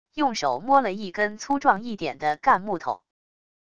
用手摸了一根粗壮一点的干木头wav音频